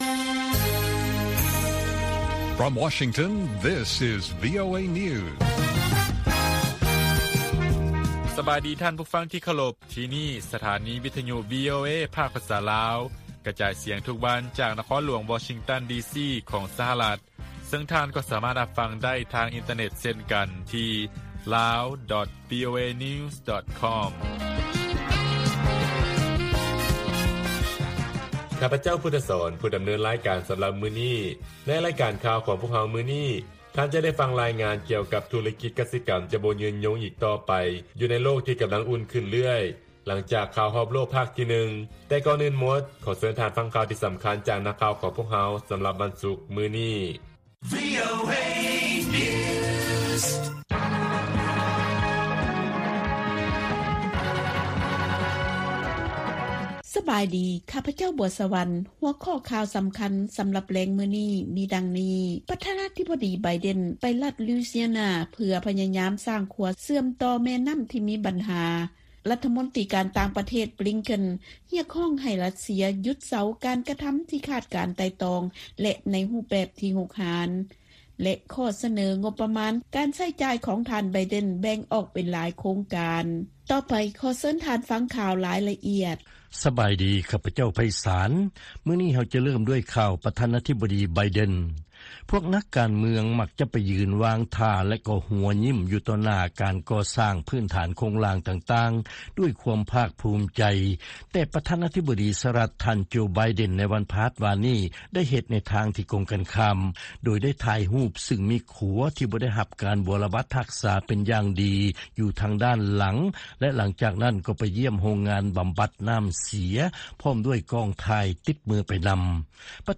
ວີໂອເອພາກພາສາລາວ ກະຈາຍສຽງທຸກໆວັນ. ຫົວຂໍ້ຂ່າວສໍາຄັນໃນມື້ນີ້ມີ: 1) ນາງງາມຈັກກະວານລາວ ມາຮອດອາເມຣິກາແລ້ວ ແລະກໍາລັງກຽມຕົວຂຶ້ນເວທີປະກວດ ນາງງາມຈັກກະວານ ປີ 2020 ໃນໄວໆນີ້. 2) ທຸລະກິດດ້ານກະສິກຳ ຈະບໍ່ຍືນຍົງໄດ້ອີກຕໍ່ໄປ ຢູ່ໃນໂລກ ທີ່ກຳລັງອຸ່ນຂຶ້ນເລື້ອຍໆ ແລະຂ່າວສໍາຄັນອື່ນໆອີກ.